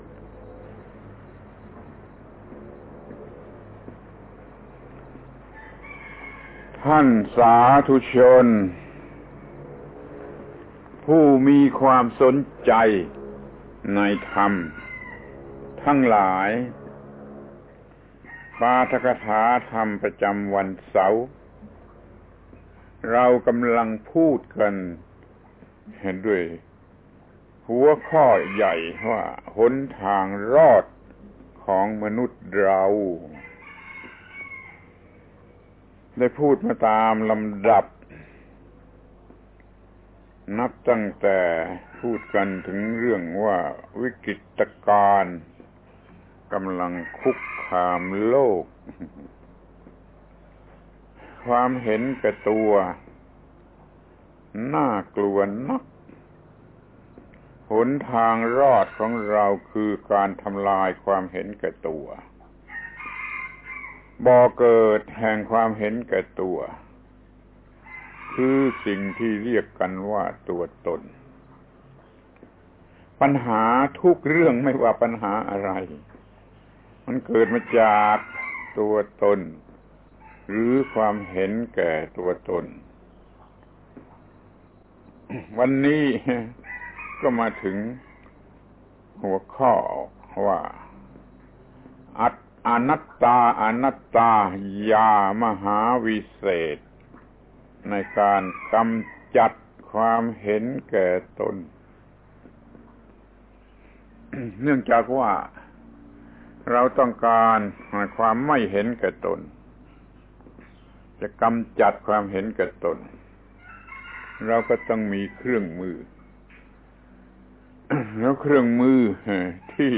ปาฐกถาธรรมทางวิทยุ ฯ หนทางรอดของมนุษย์ ครั้งที่ 6 อนัตตาเป็นยามหาวิเศษในการกำจัดความเห็นแก่ตัว